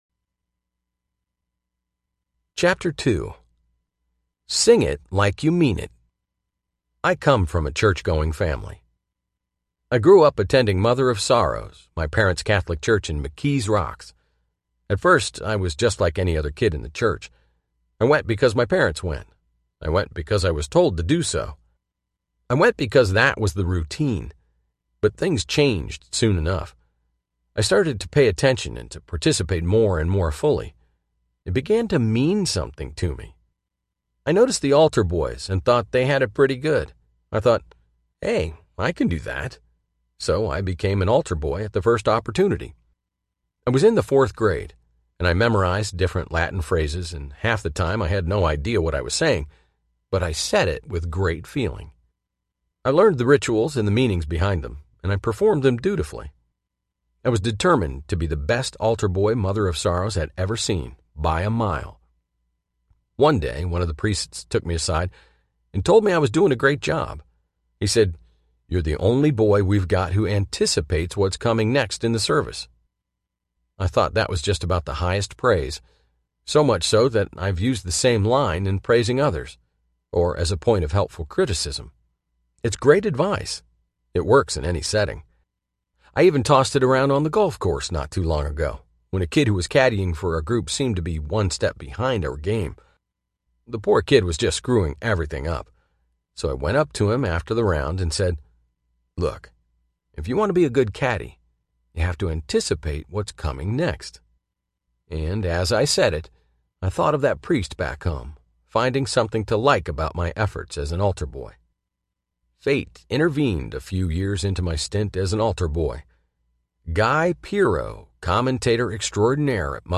Every Other Monday Audiobook
7.0 Hrs. – Unabridged